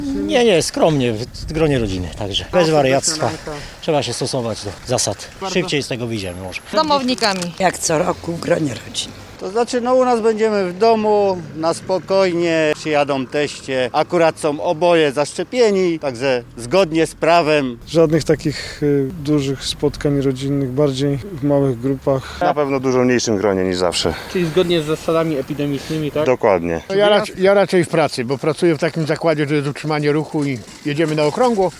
Święta w gronie rodziny [SONDA]